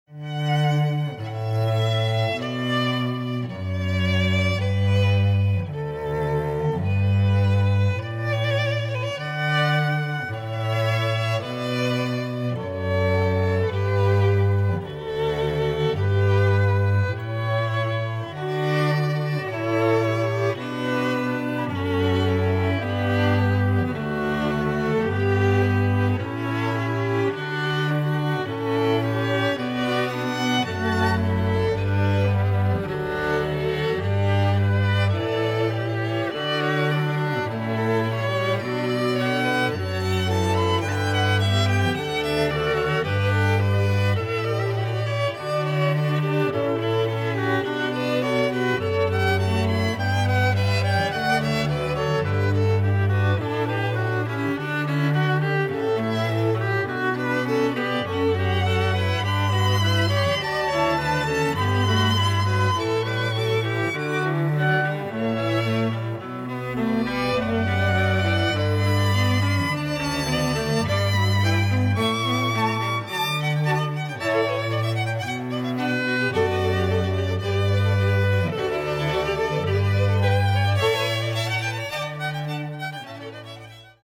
A modern all-female string quartet bringing a rock 'n' roll edge to classical music.
Acoustic and Electric String Quartet for Functions